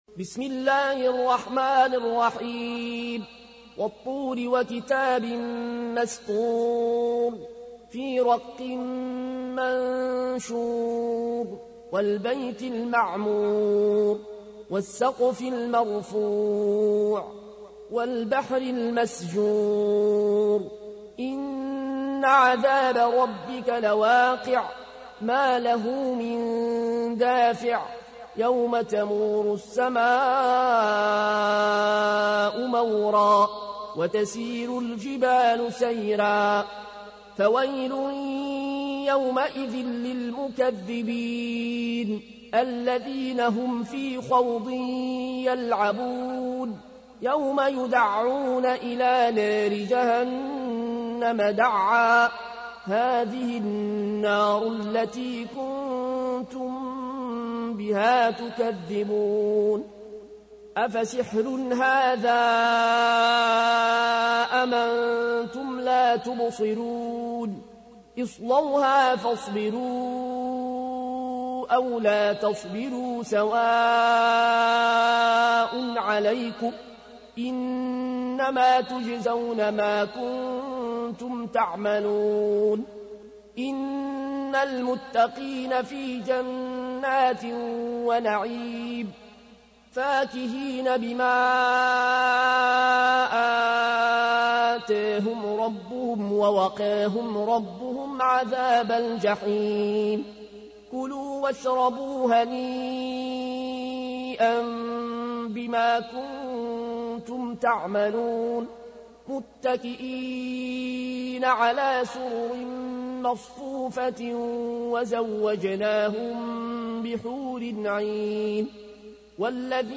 تحميل : 52. سورة الطور / القارئ العيون الكوشي / القرآن الكريم / موقع يا حسين